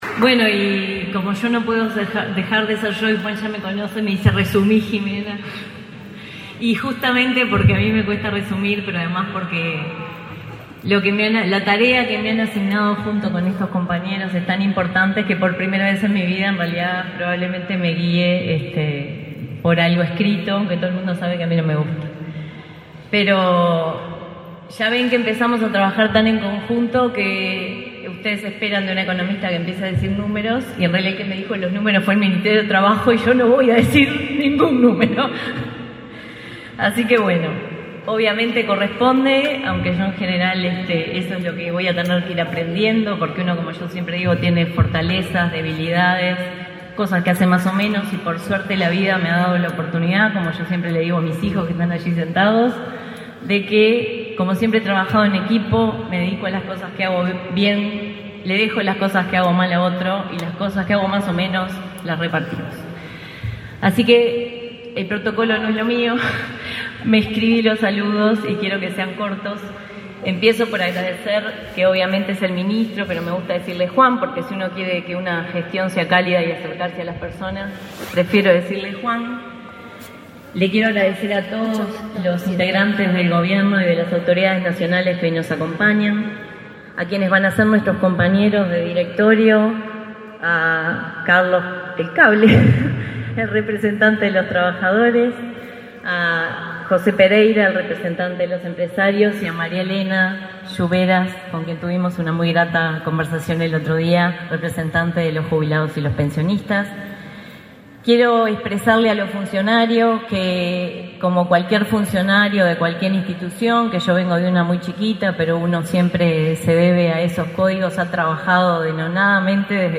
Palabras de la presidenta del BPS, Jimena Pardo
Con la presencia del ministro de Trabajo y Seguridad Social, Juan Castillo, se realizó, este 27 de marzo, el acto de asunción de las autoridades del